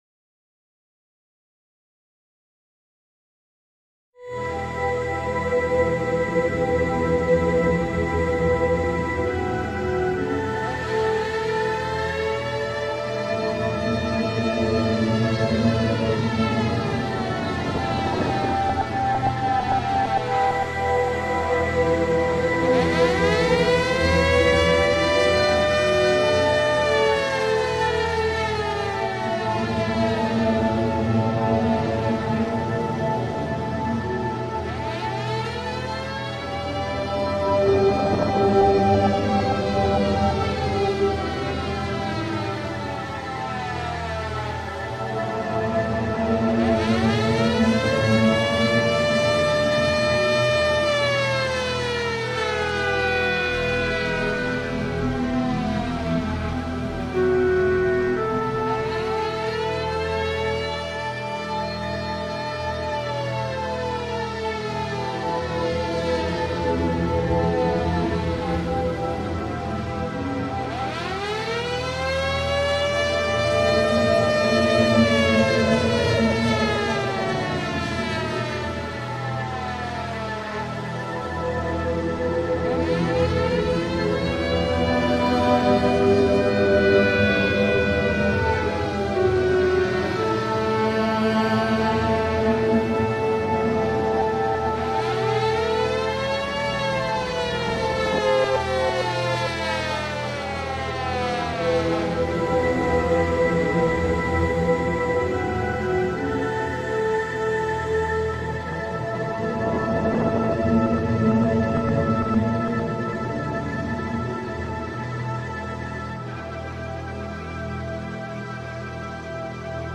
All...ambienty and stuff...
I kind of wanted an epic/'we're screwed' sound, considering they were battling a dragon... IV Shattered Hoof Gawd's Reign (7.24 - 9.50) Very similar to II, except different instrumentation and key.